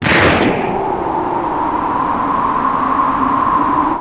bangblow.wav